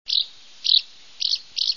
Jaskółka oknówka - Delichon urbicum